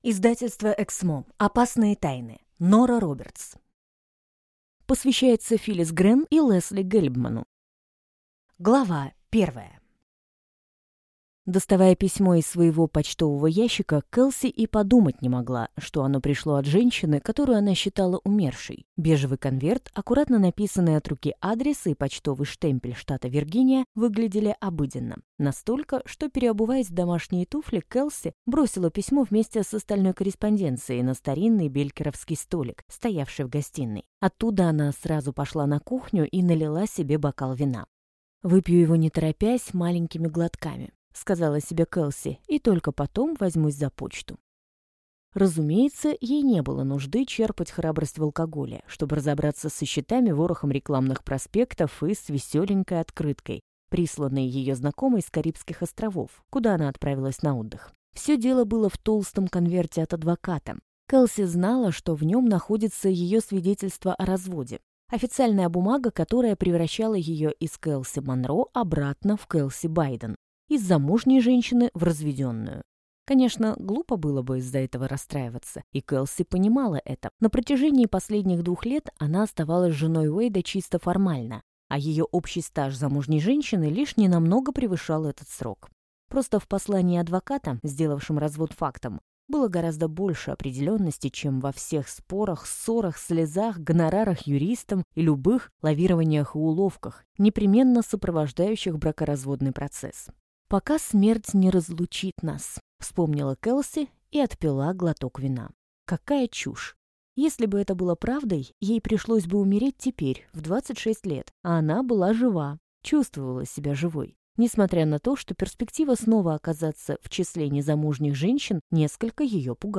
Аудиокнига Опасные тайны | Библиотека аудиокниг